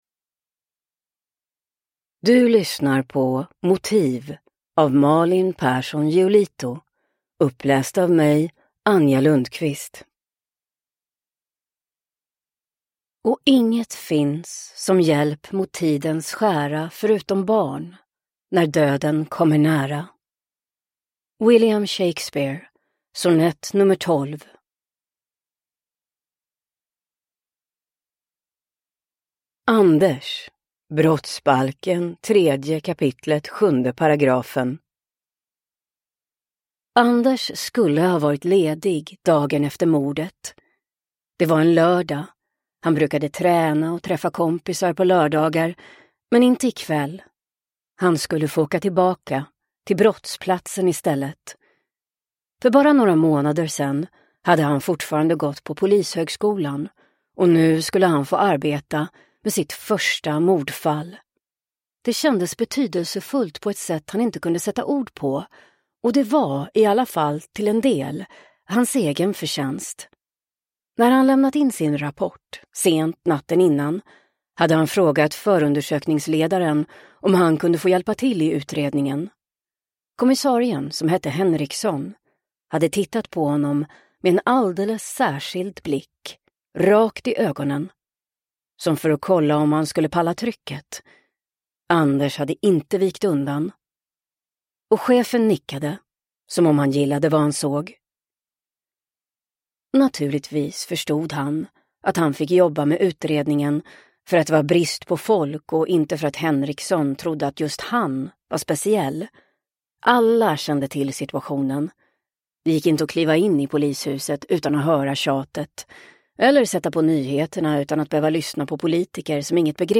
Motiv – Ljudbok